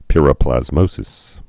(pĭrə-plăz-mōsĭs)